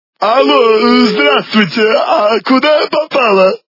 » Звуки » Смішні » Ало! Здравствуйте! - А куда я попала?
При прослушивании Ало! Здравствуйте! - А куда я попала? качество понижено и присутствуют гудки.